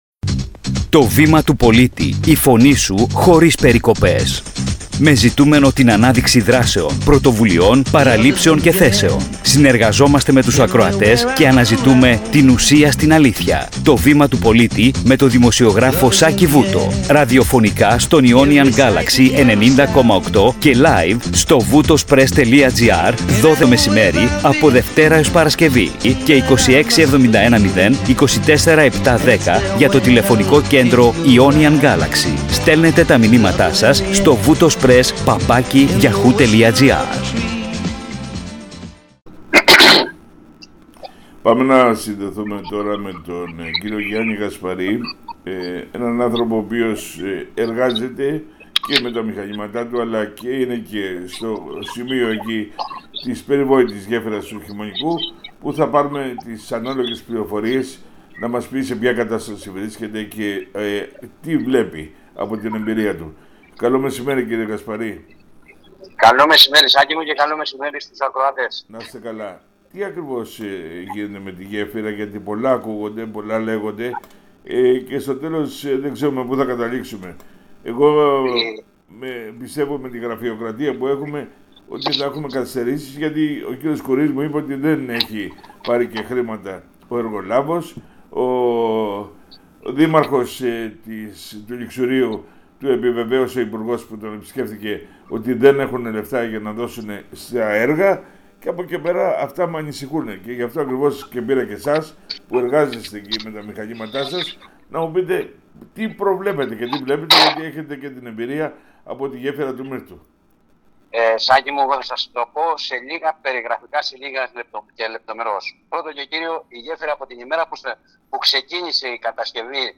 ΡΕΠΟΡΤΑΖ ΚΑΙ ΦΩΤΟ ΑΠΟ ΤΟ ΕΡΓΟ ΣΗΜΕΡΑ 6 6 2024